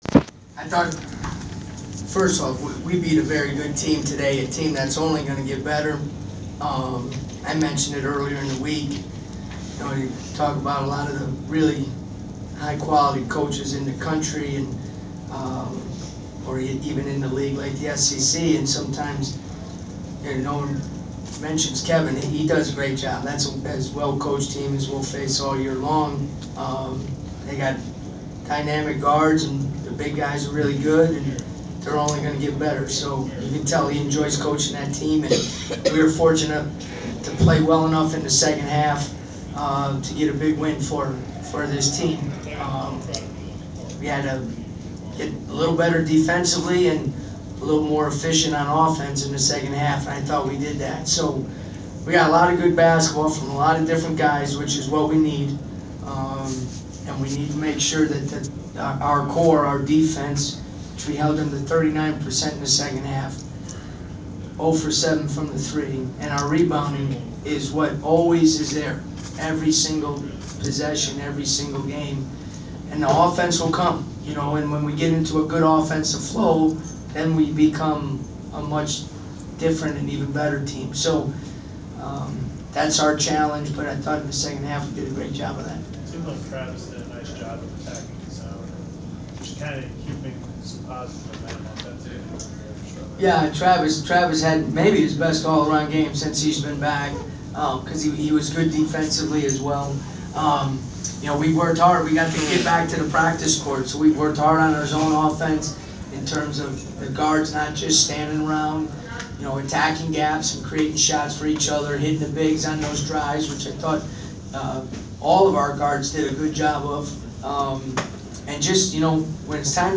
We attended the post game press conference of Georgia Tech men’s basketball head coach Brian Gregory following his team’s 65-60 home win over Vanderbilt on Dec. 20.